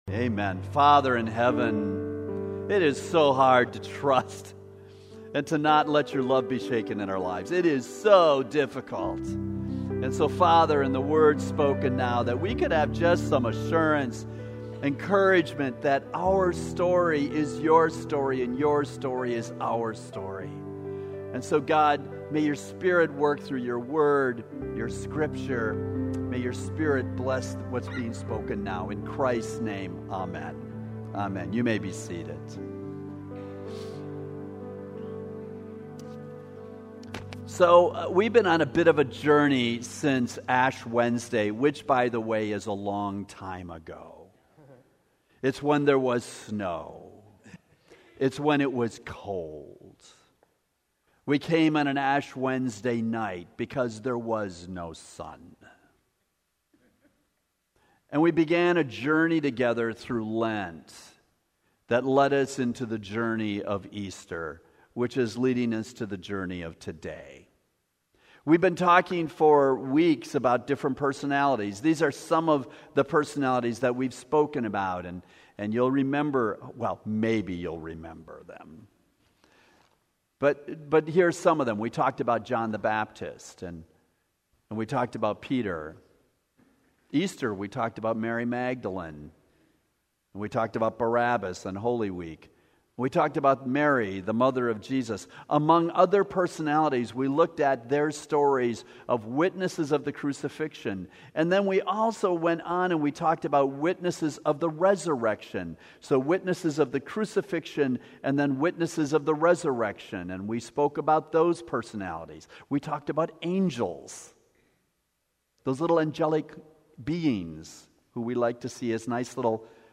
Contemporary Worship